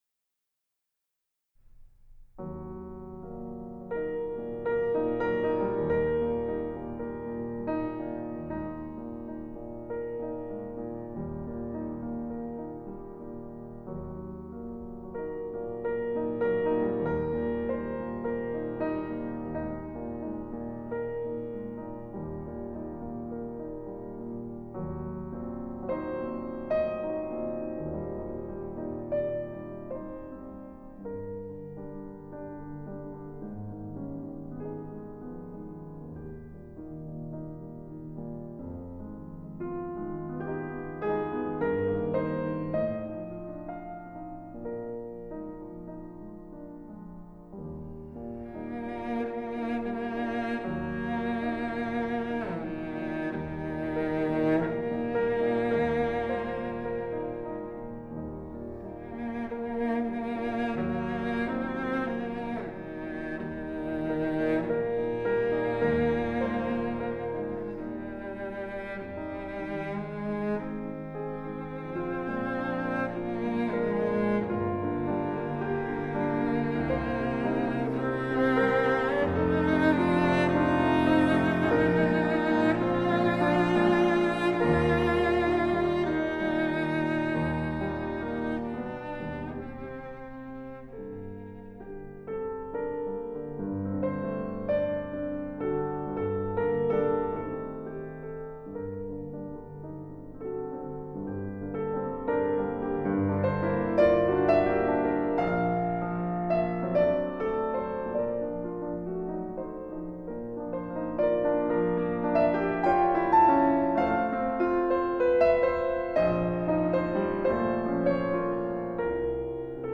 ★ 於加拿大魁北克Domaine Forget音樂廳錄製！